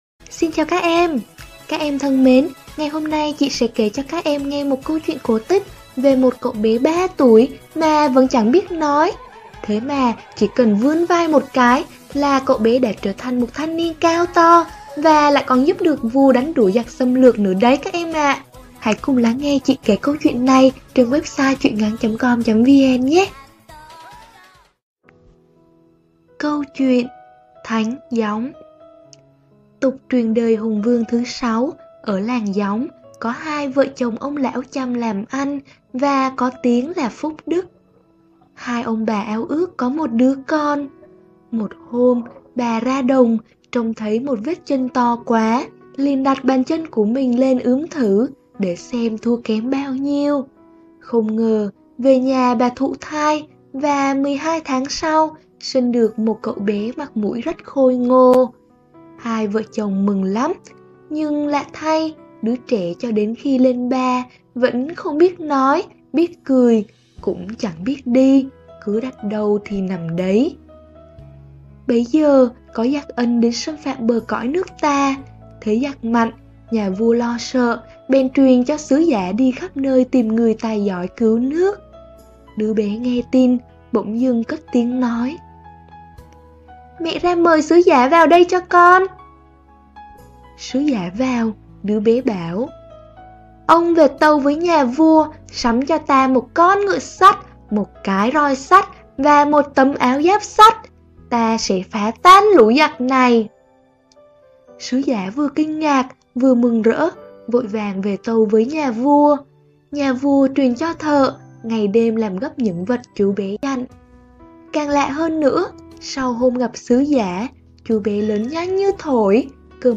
Sách nói | Thánh Gióng